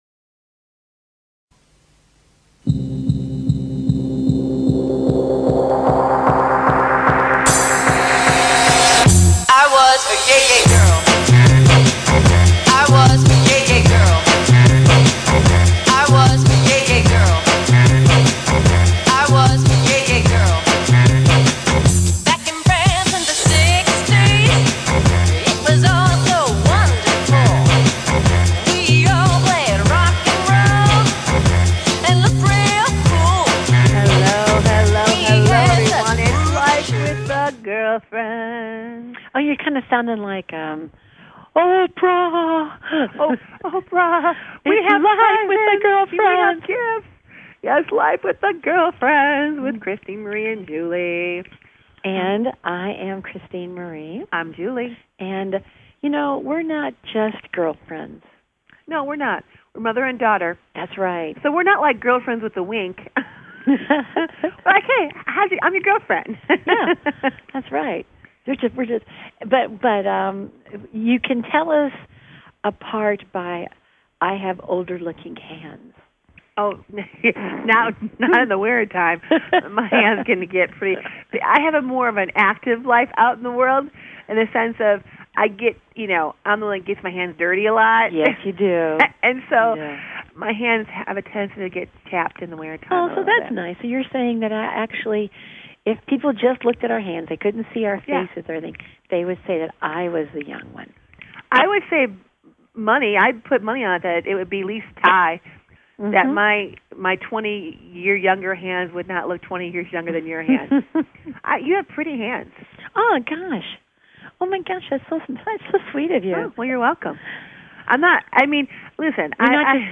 Interview with guest